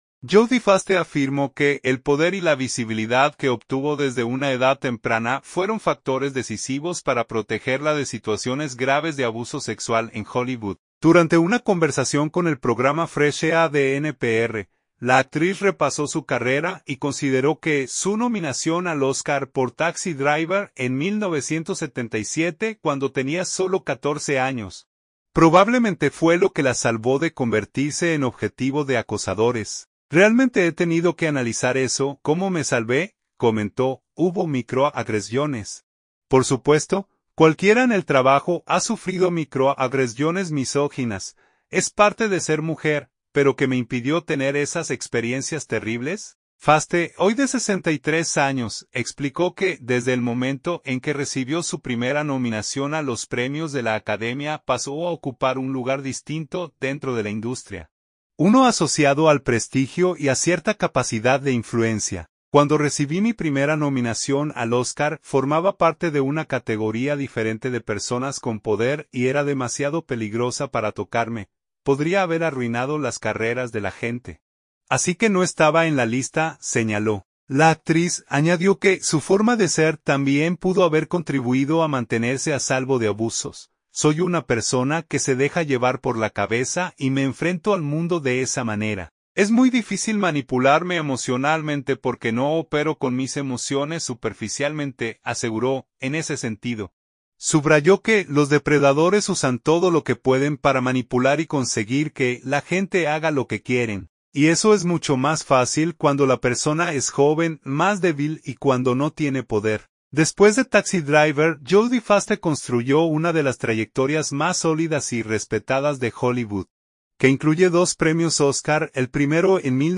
En entrevista con el programa Fresh Air, Foster repasó su carrera y habló sobre lo que probablemente la “salvó” de convertirse en objetivo de acosadores